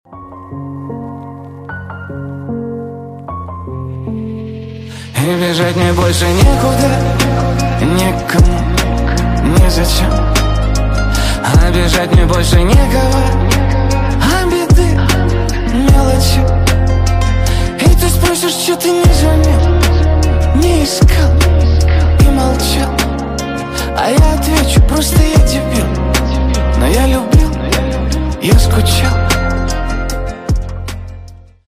Тэги: красивыенежныелучшие2022